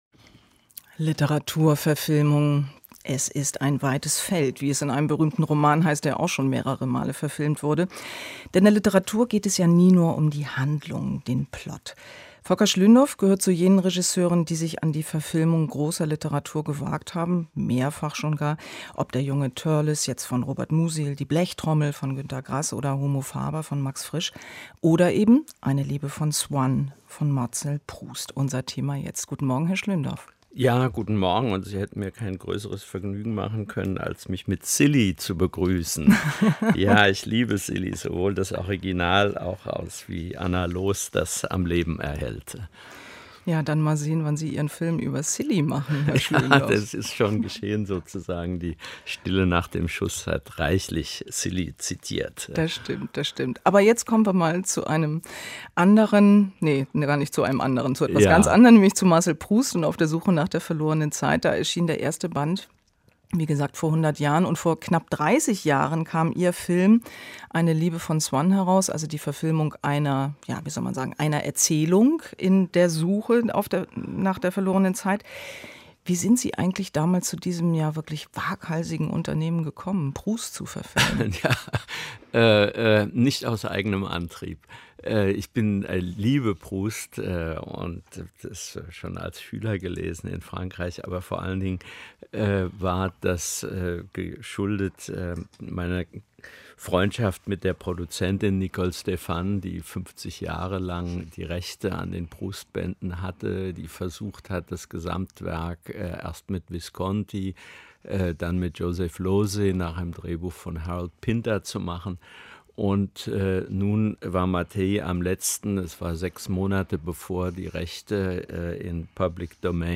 dra-audio) - Ein Gespräch mit Volker Schlöndorff